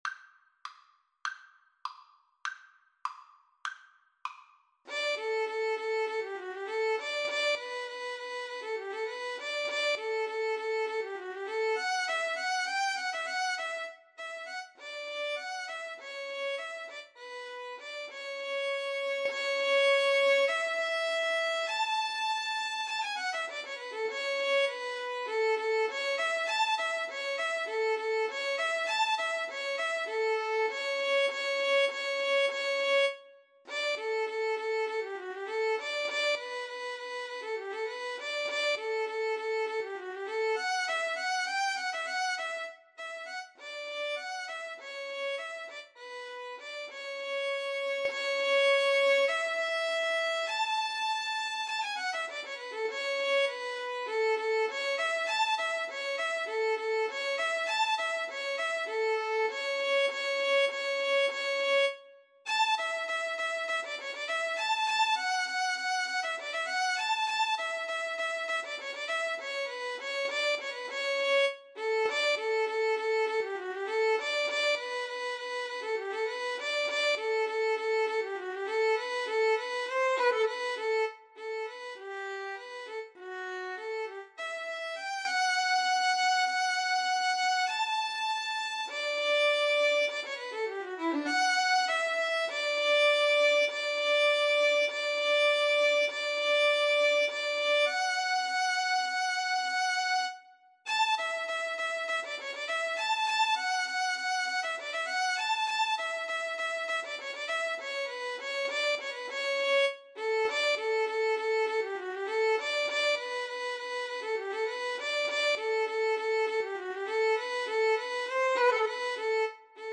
Play (or use space bar on your keyboard) Pause Music Playalong - Player 1 Accompaniment reset tempo print settings full screen
~ = 100 Allegretto
D major (Sounding Pitch) (View more D major Music for Violin Duet )
Classical (View more Classical Violin Duet Music)